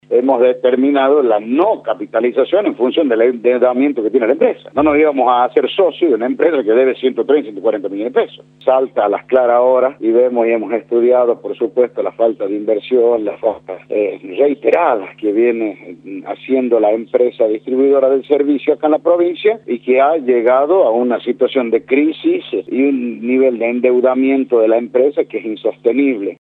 Oscar Chamia, diputado provincial, por Radio La Red
“Hay informes, de hace cuatro años adonde se informaban de esta situación. El EUCOP tomó las precauciones necesarias para hacer los controles que correspondían. Todos esos informes están hechos por el ente”, indicó en diálogo con Radio La Red.